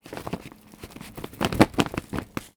R - Foley 86.wav